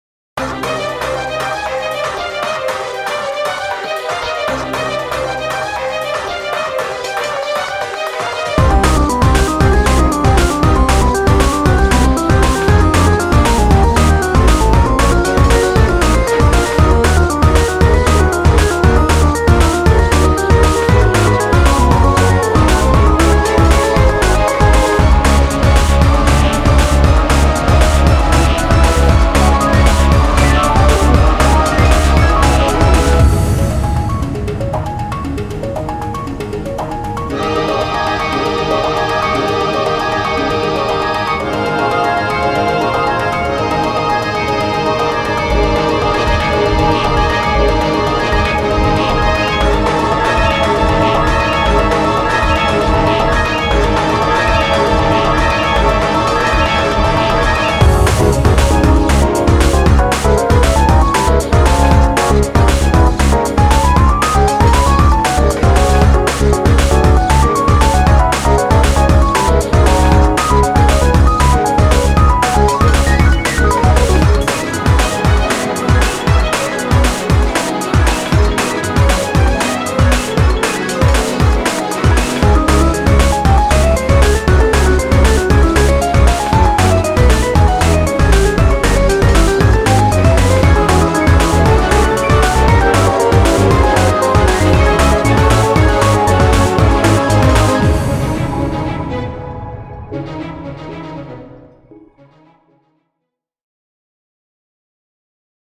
BPM117-234
Audio QualityPerfect (High Quality)